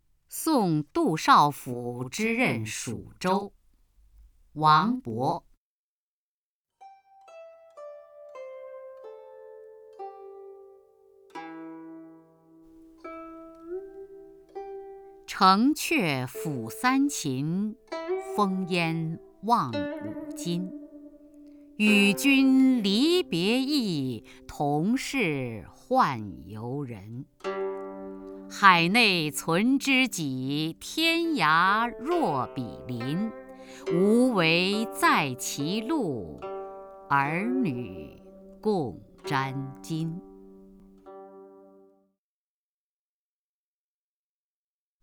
首页 视听 名家朗诵欣赏 雅坤
雅坤朗诵：《送杜少府之任蜀州》(（唐）王勃)